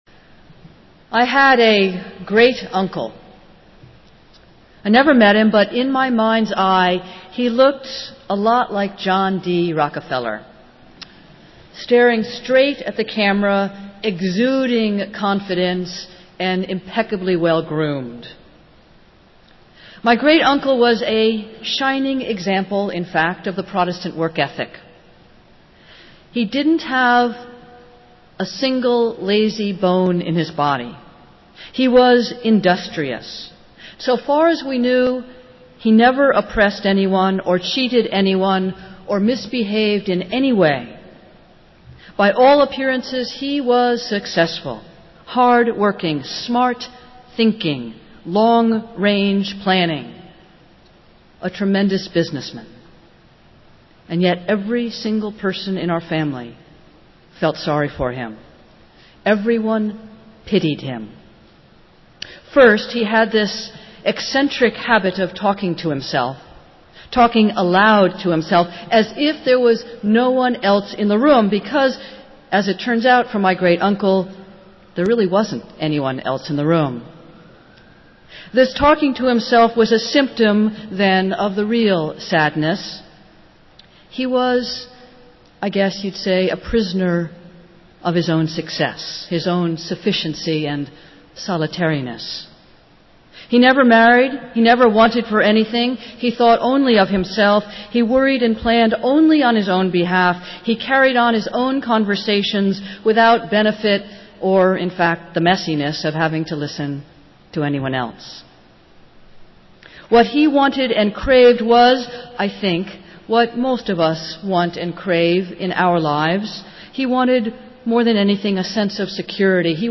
Festival Worship - Tenth Sunday after Pentecost